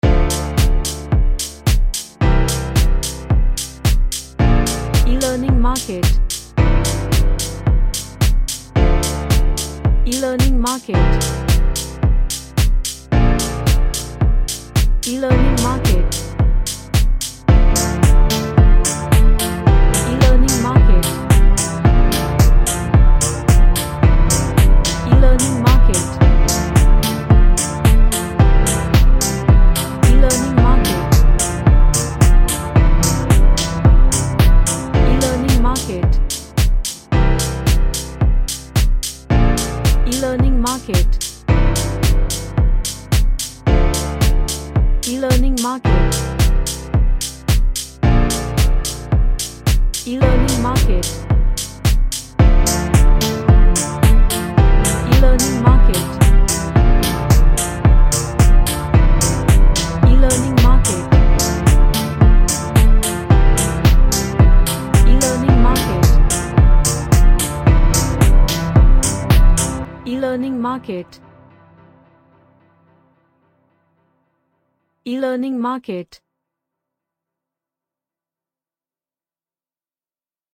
A nice melodic arped track.
Happy